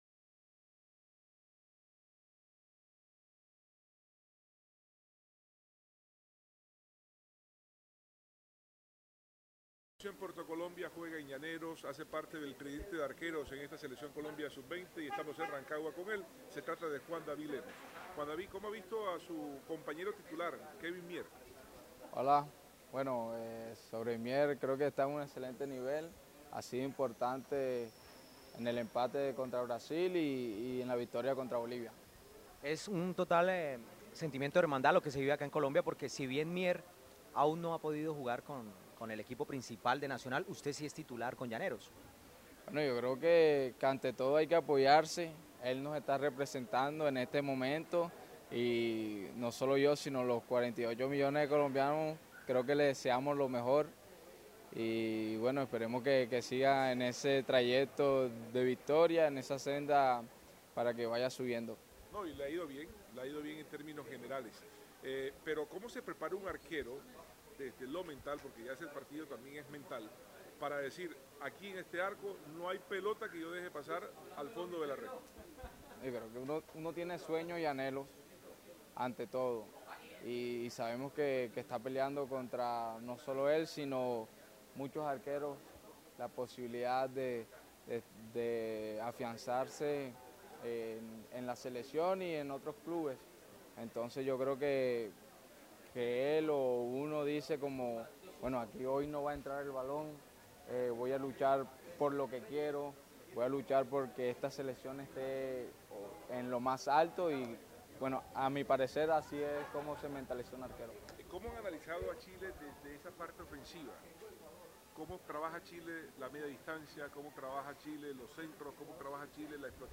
Luego del entrenamiento, cuatro jugadores atendieron a la prensa en su concentración: